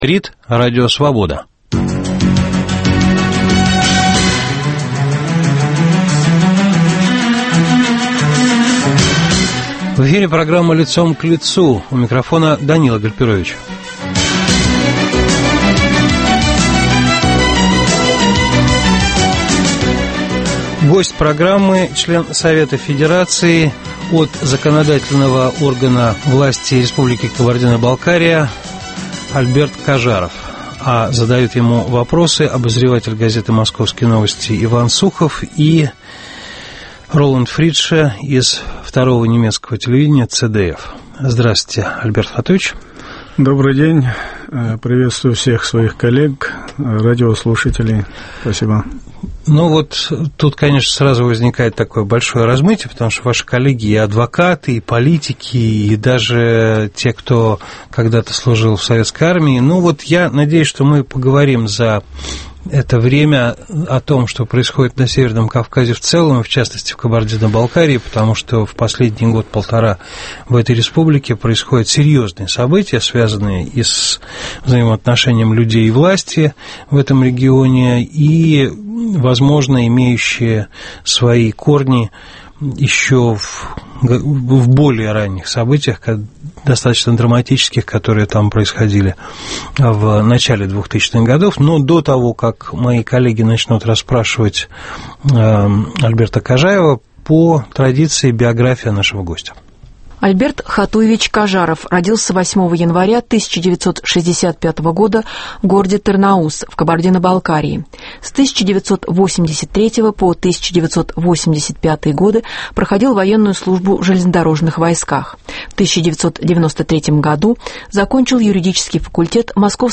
В программе - член Совета Федерации от законодательной власти Республики Кабардино-Балкария Альберт Кажаров.